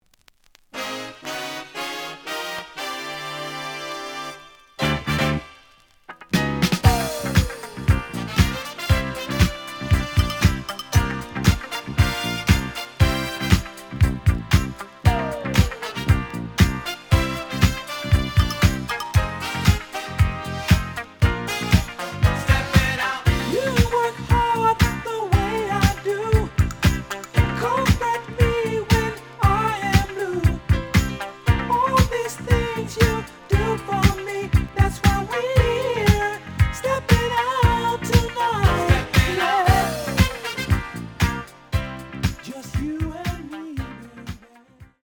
The audio sample is recorded from the actual item.
●Format: 7 inch
●Genre: Funk, 80's / 90's Funk